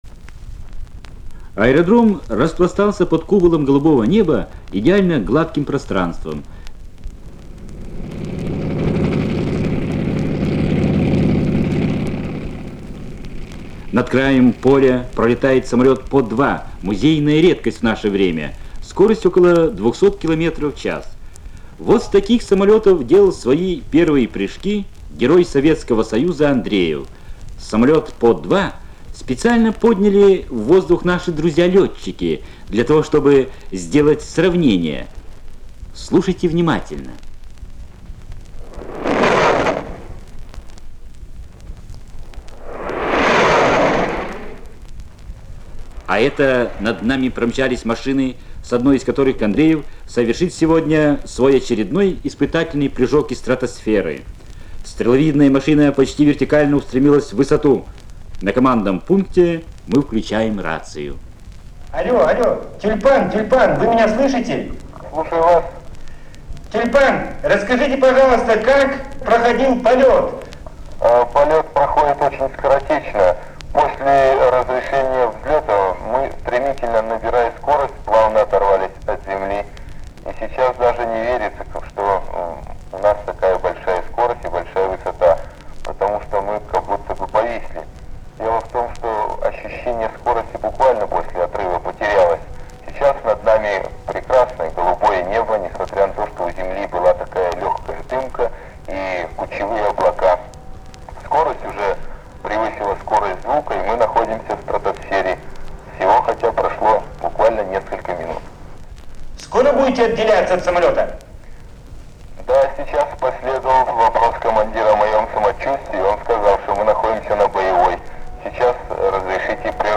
Командный пункт.
Звуковая страница 3 - Репортаж из стратосферы.